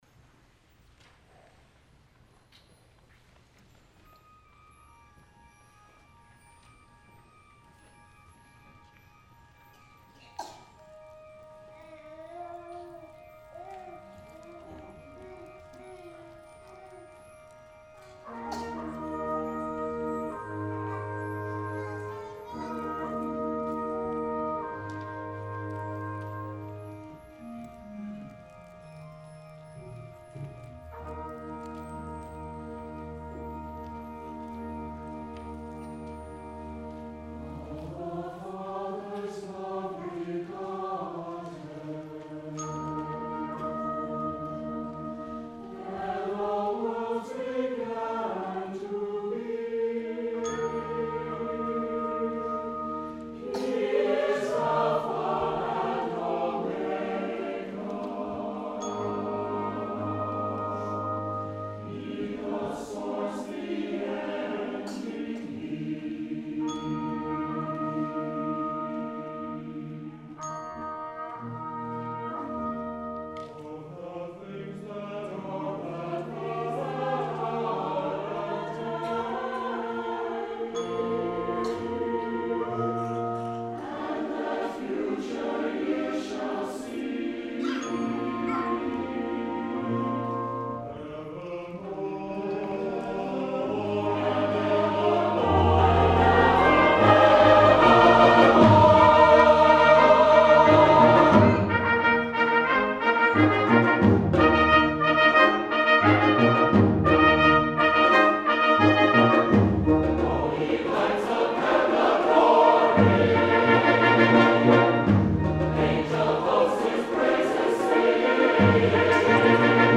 for SATB Chorus and Brass Quintet, Percussion, and Organ (2009)
brass version